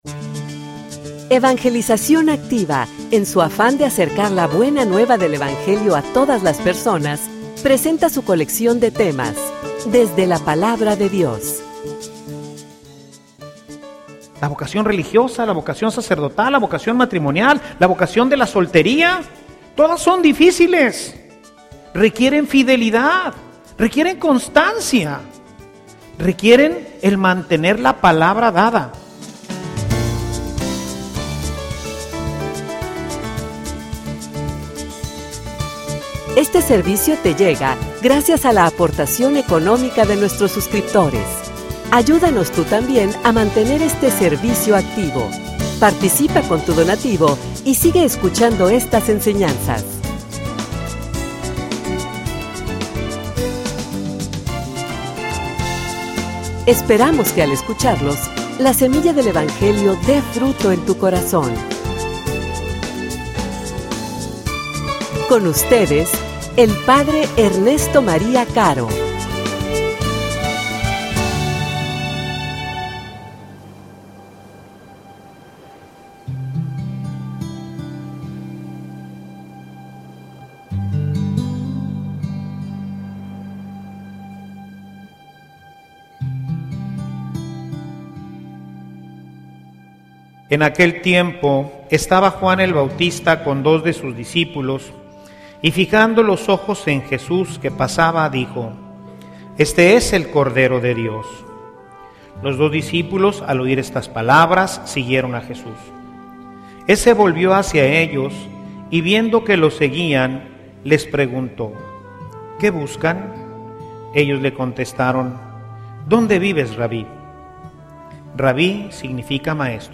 homilia_Llamado_a_la_santidad.mp3